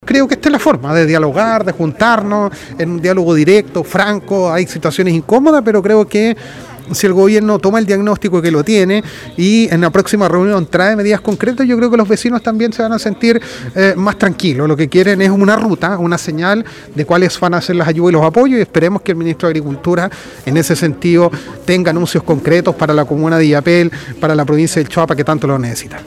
AUDIO : Alcalde Denis Cortés Aguilera